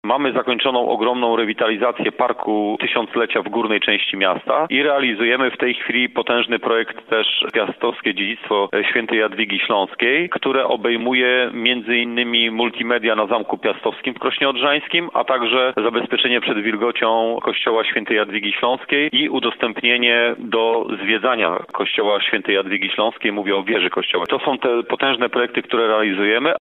Jak mówi burmistrz Krosna Odrzańskiego Marek Cebula, w tej chwili realizowanych jest wiele inwestycji, które skupiają się nie tylko na wyglądzie miejscowości, ale również na bezpieczeństwie mieszkańców: